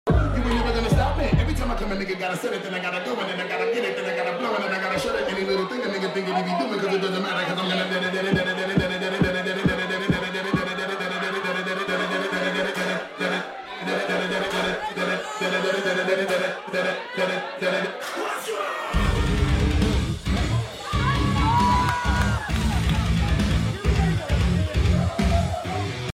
at a wedding